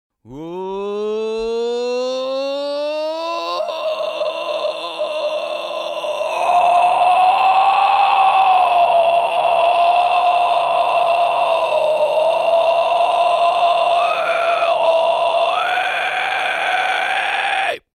Fry Scream complexe Medium atonal
20Fry-Scream-complexe-_-Medium_atonal-.mp3